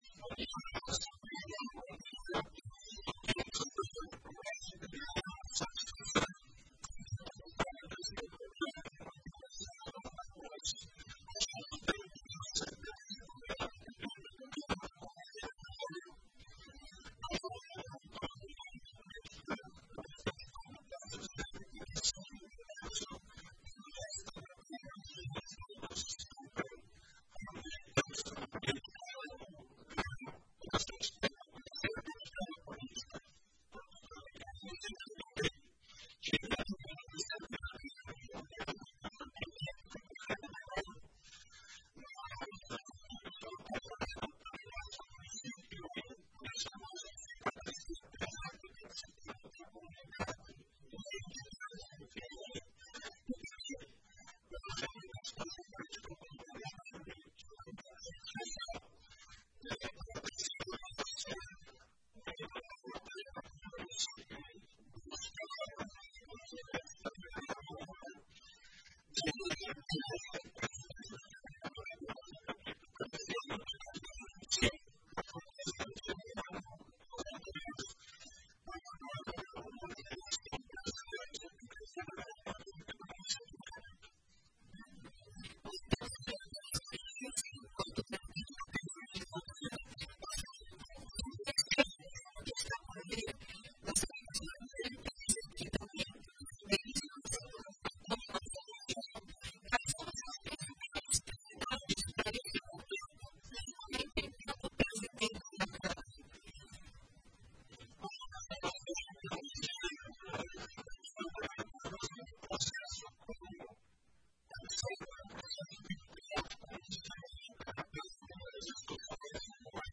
Durante entrevista ao programa Rádio Ligado, da Rádio Progresso, o pedetista disse que sempre teve envolvimento com a política, e que pretende conduzir os debates com os demais vereadores da forma mais democrática possível.
ENTREVISTA-BURMANN-CATUIPE.mp3